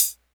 07_Hats_03_SP.wav